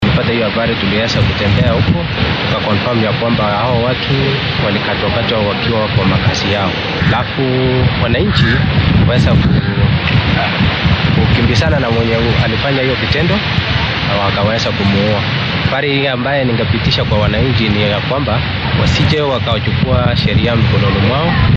Taliyaha booliiska ee deegaankaasi Bernard Bitok ayaa dhacdadan iyo khasaaraha ka dhashay warbaahinta u xaqiijiyay.